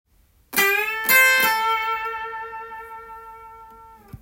③のチョーキングはチョーキングしながら小指で１弦を
チョーキングした音を残しながら小指で弾いていくので
音が重なるので　その分音圧が出てソロが盛り上がります。